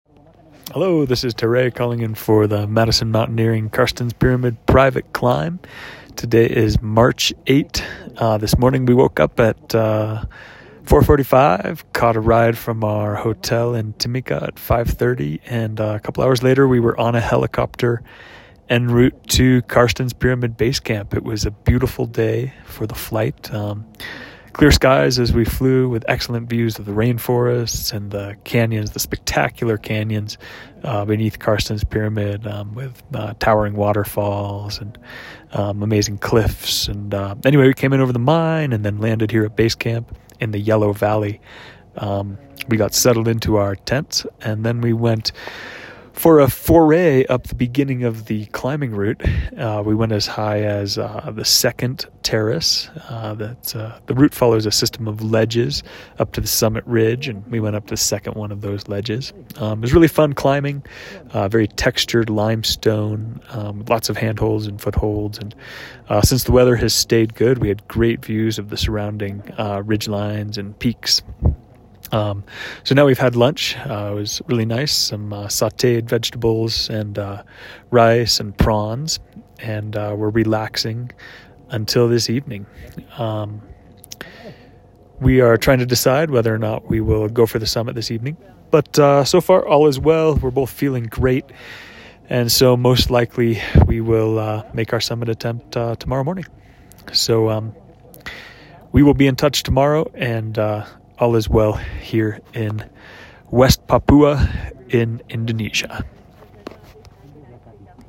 From the Yellow Valley Base Camp on Carstensz Pyramid!